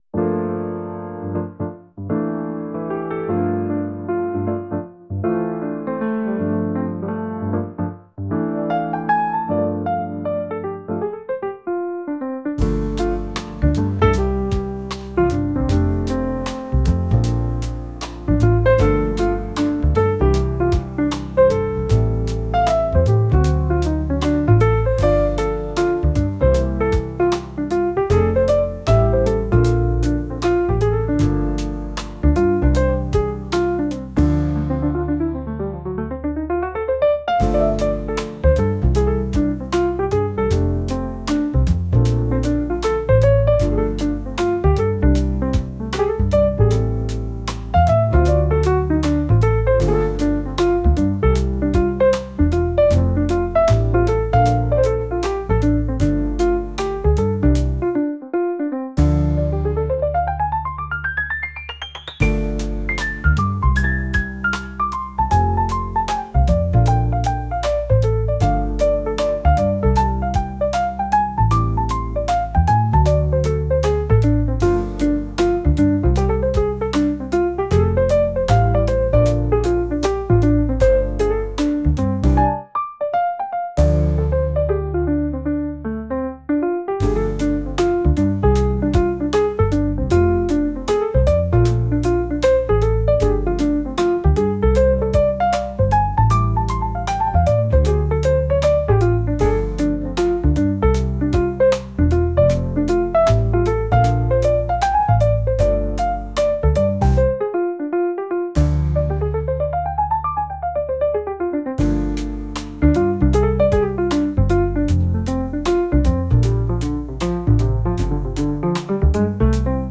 ジャンルJAZZ
楽曲イメージBar, ゆったり, カフェ, ムーディー, , 大人
利用シーン店舗BGM
夜になると、窓の向こうから漏れてくるピアノジャズが、知らぬ間に心の隙間を埋めていく。